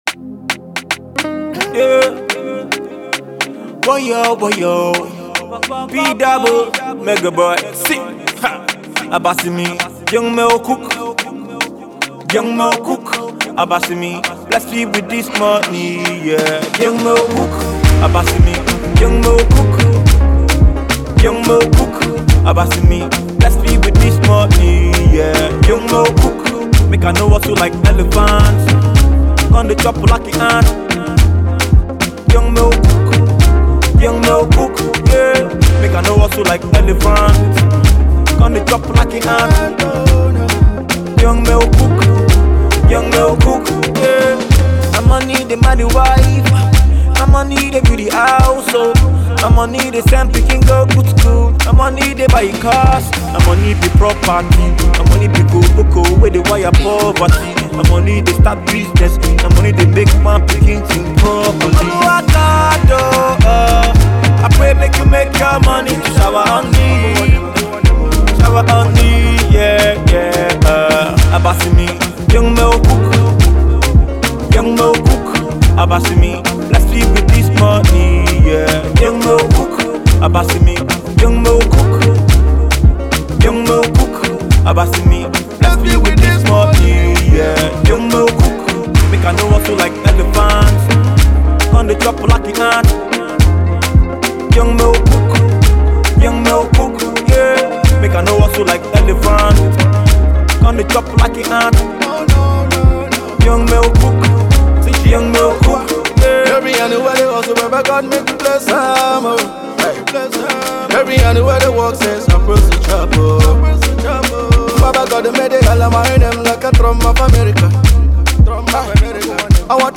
prayer song